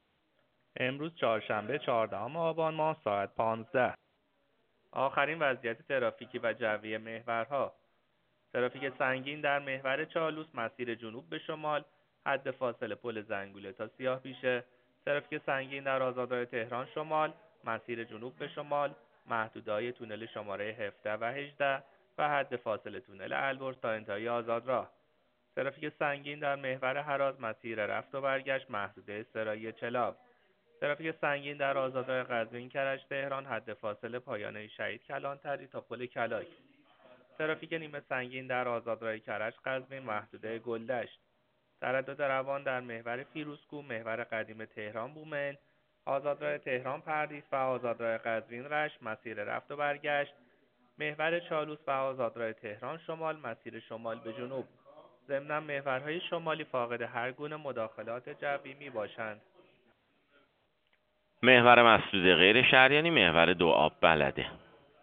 گزارش رادیو اینترنتی از آخرین وضعیت ترافیکی جاده‌ها ساعت ۱۵ چهاردهم آبان؛